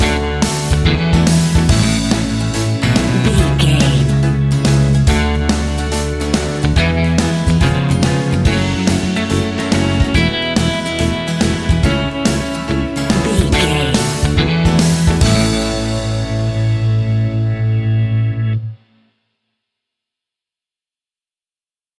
Uplifting
Ionian/Major
pop rock
indie pop
fun
energetic
acoustic guitars
drums
bass guitar
electric guitar
piano
organ